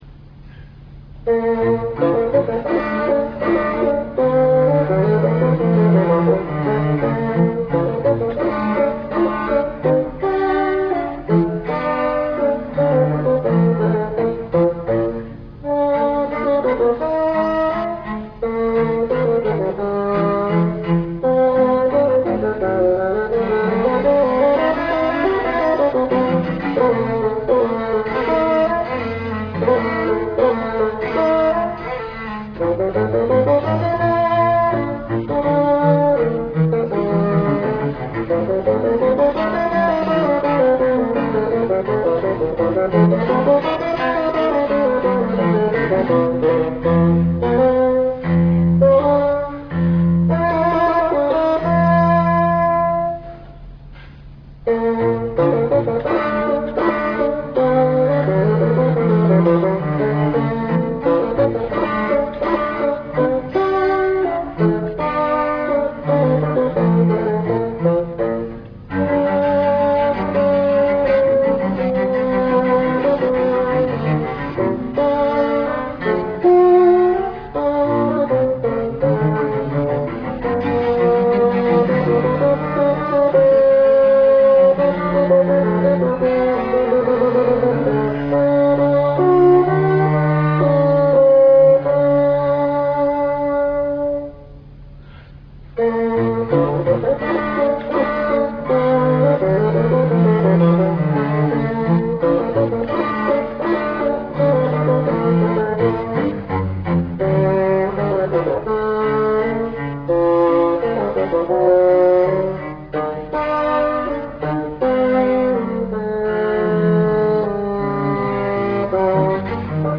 １９９９年８月８日・八幡市文化センター小ホールにて行われた八幡市民オーケストラ室内楽の集い〜真夏の真昼のコンサート〜での演奏。
（PCM 11,025 kHz, 8 ビット モノラル/RealPlayer3.0以上でお聞き下さい）